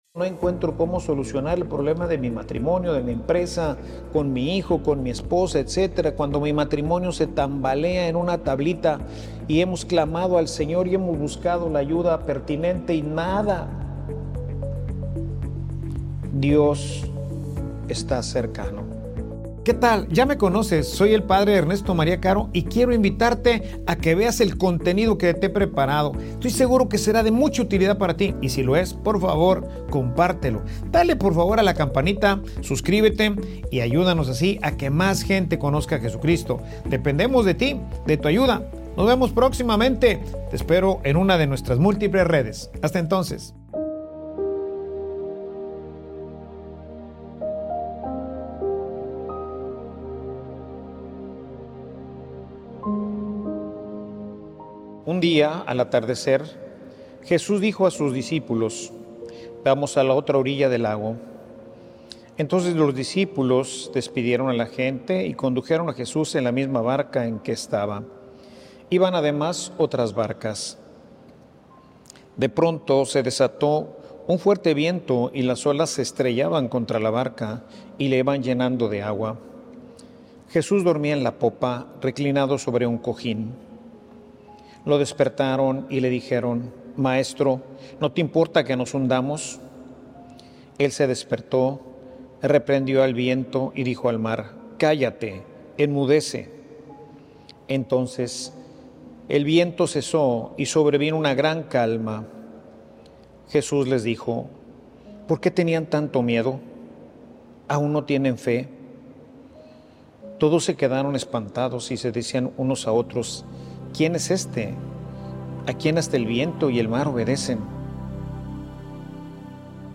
Homilia_Ya_veran_lo_que_dios_va_a_hacer.mp3